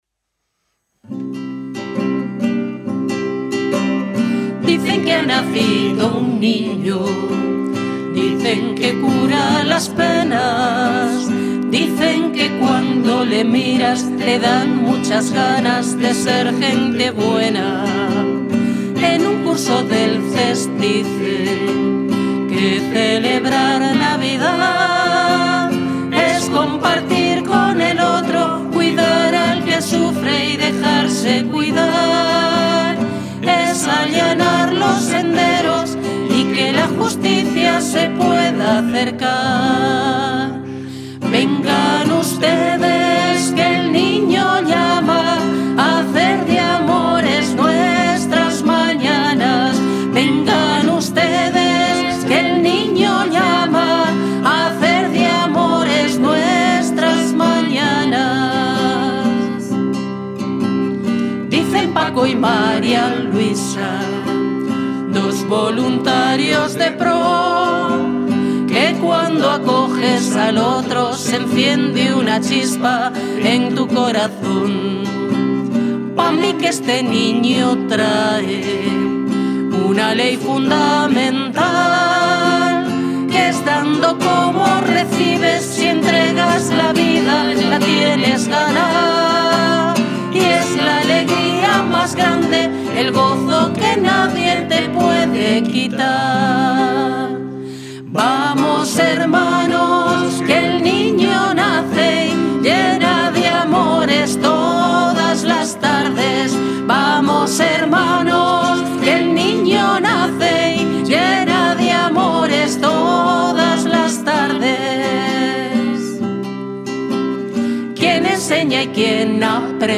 El equipo de Desarrollo de Personas de Cáritas ha sido el encargado de interpretar esta pieza musical, cuyo contenido está cargado de mensajes de solidaridad, acogida y justicia social.
Villancico Desarrollo de Personas